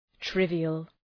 Προφορά
{‘trıvıəl}